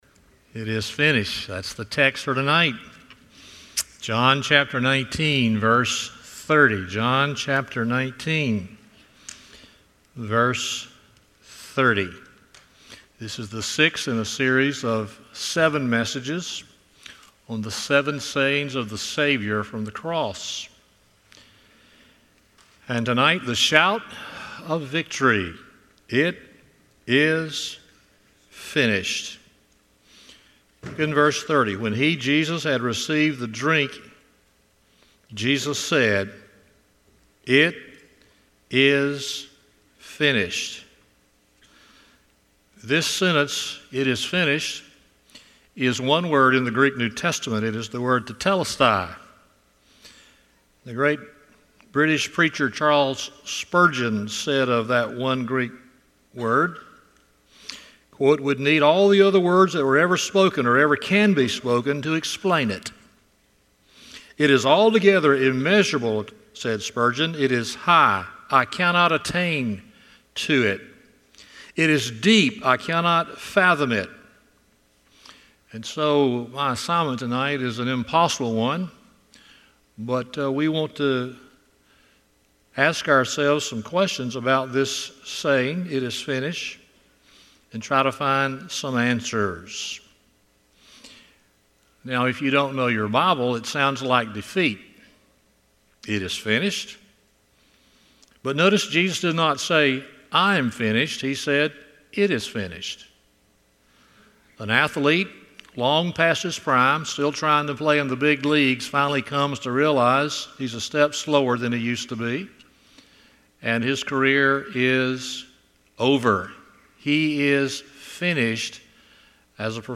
John 19:30 Service Type: Sunday Evening "It is finished" 1.